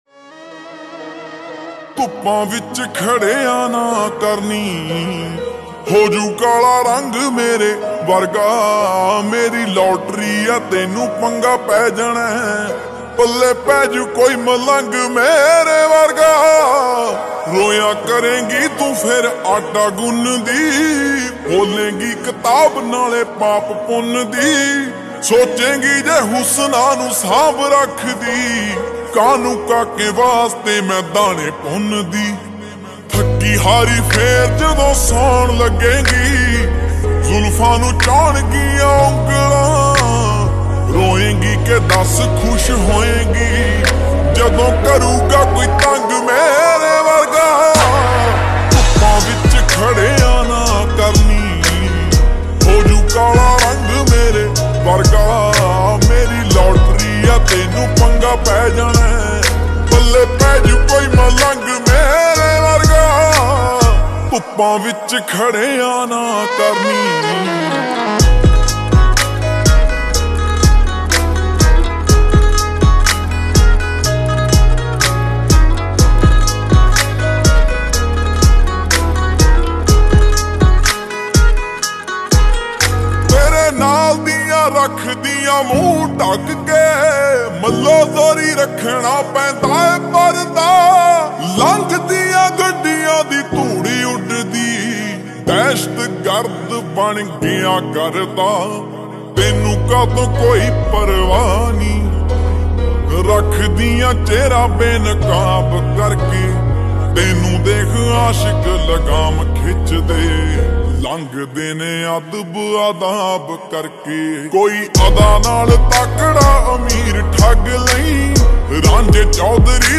slow and reverb